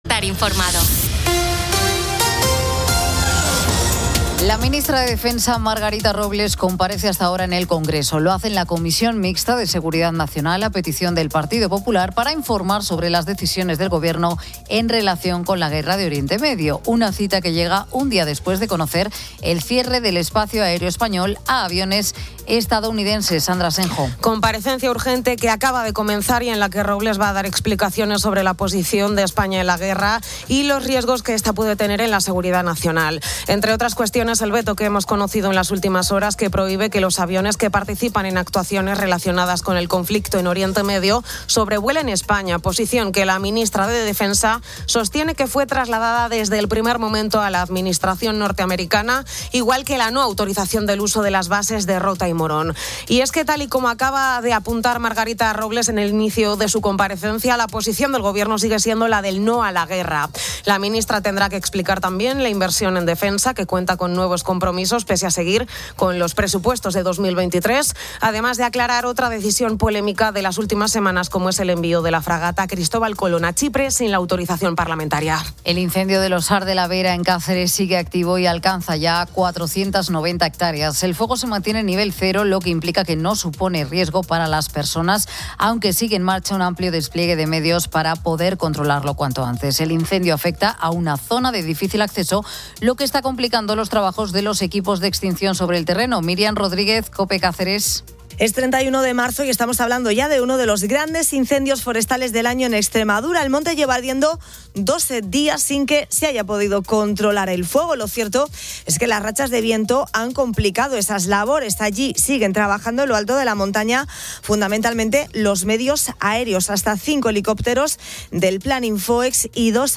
La ministra de Defensa, Margarita Robles, explica la postura de España ante la guerra en Oriente Medio, incluyendo el veto al sobrevuelo de aviones militares de EE. UU. y la negativa al uso de bases, reafirmando el "no a la guerra". Se desarticula una gran red de narcotráfico en Ceuta, con 27 detenidos y 17 toneladas de droga.